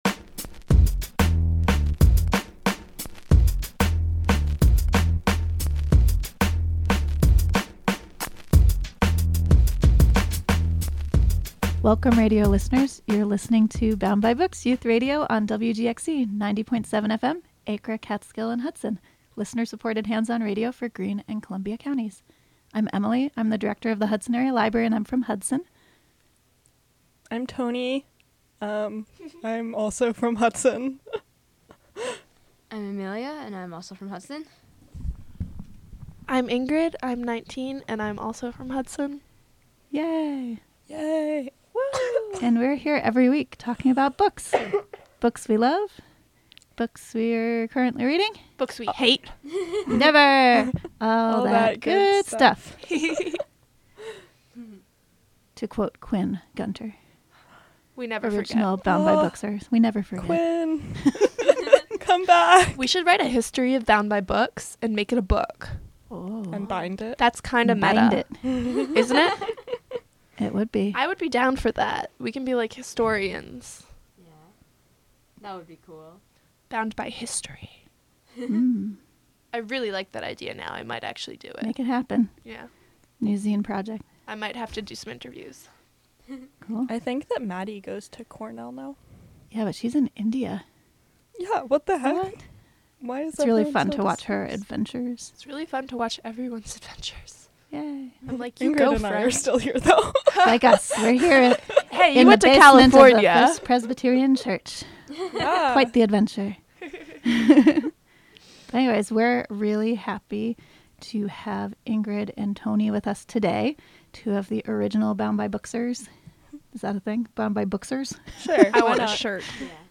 Recorded in the WGXC Hudson studio, Dec. 31.